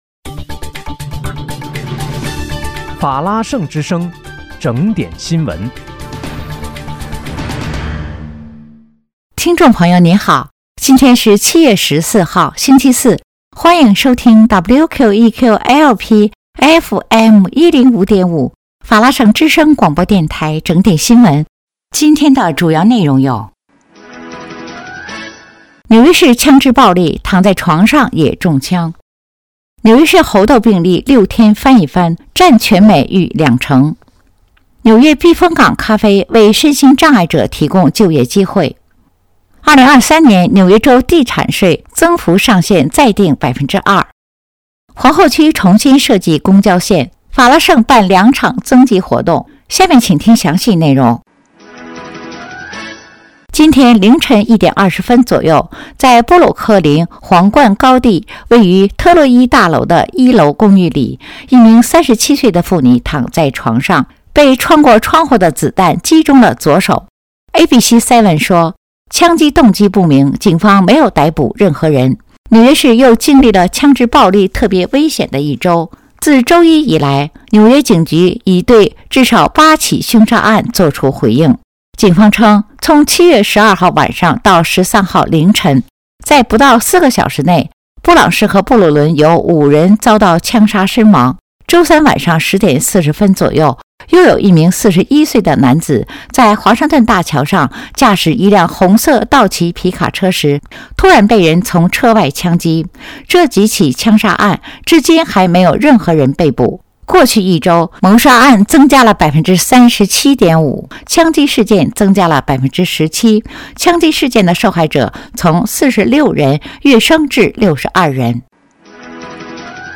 7月14日（星期四）纽约整点新闻
在听众朋友您好！今天是7月14号，星期四，欢迎收听WQEQ-LP FM105.5法拉盛之声广播电台整点新闻。